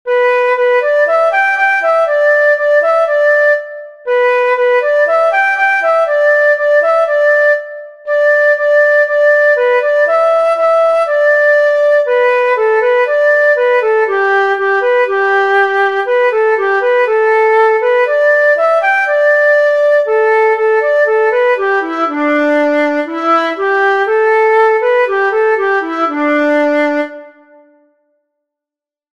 Chinesisches Lied.
chinesisches_lied.mp3